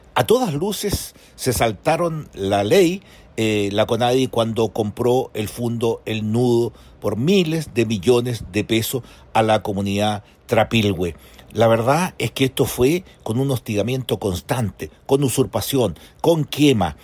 Por su parte, el diputado de Renovación Nacional, Miguel Mellado, sostiene que fue irregular la compra del predio El Nudo por parte de la Conadi, agregando que “a todas luces se saltaron la ley“.